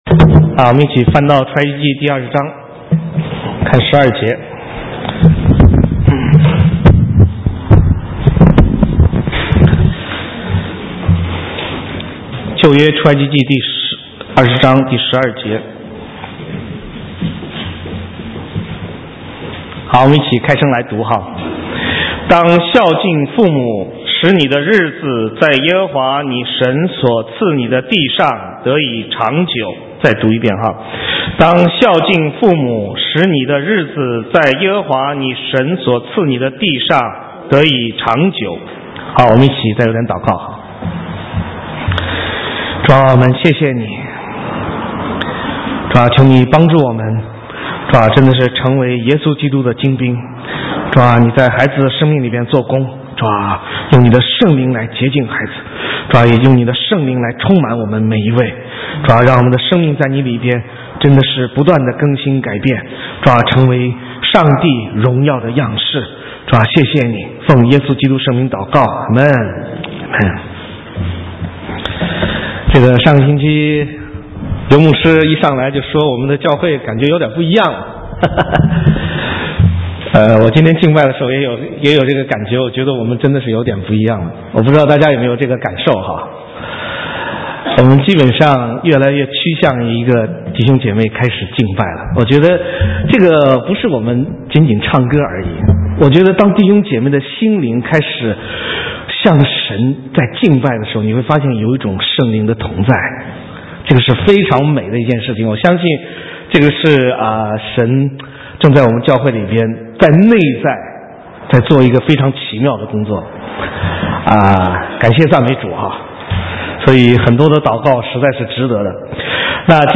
神州宣教--讲道录音 浏览：祝各位母亲快乐 (2011-05-08)